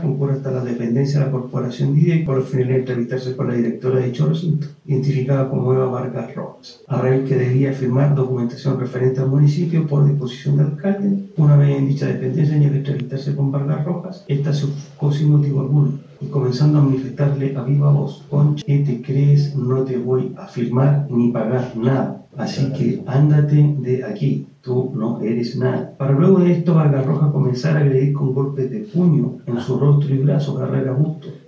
El fiscal Gonzalo Burgos, describió la dinámica de la denuncia con base al parte policial de Carabineros.